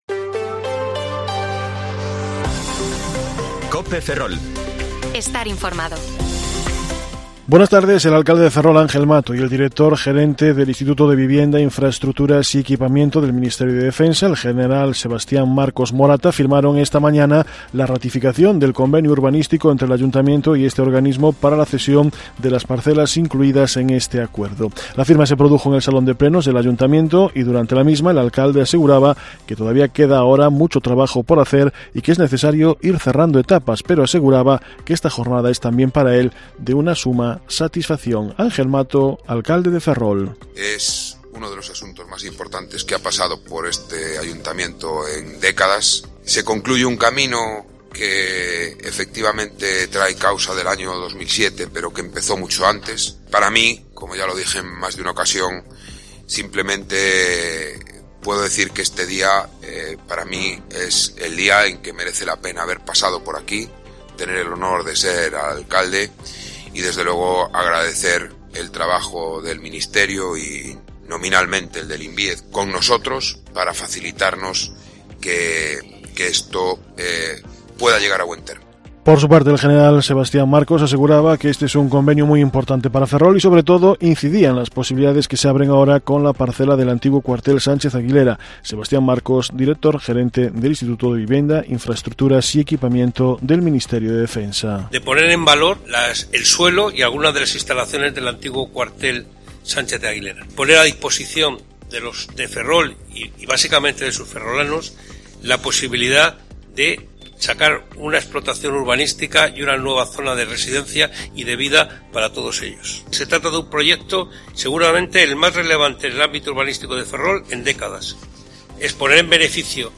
Informativo Mediodía COPE Ferrol 21/3/2023 (De 14,20 a 14,30 horas)